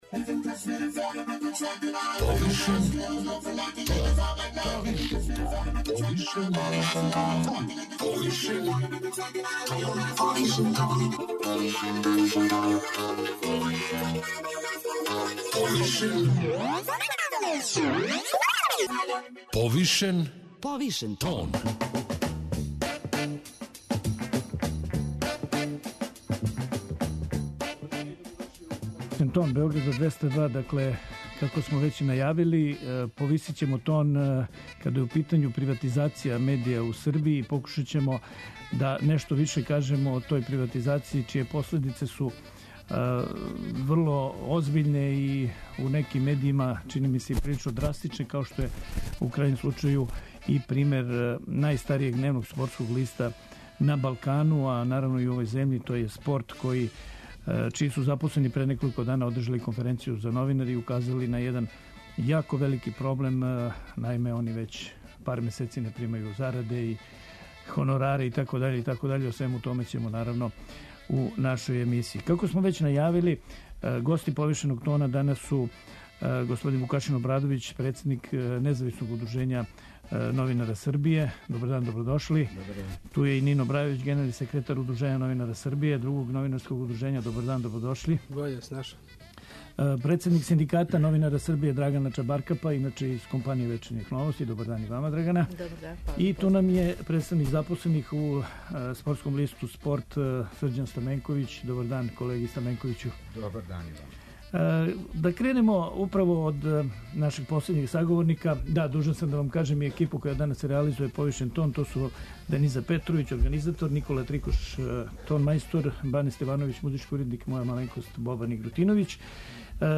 О томе ће говорити представници новинарских удружења